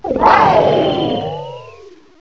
sovereignx/sound/direct_sound_samples/cries/miraidon.aif at master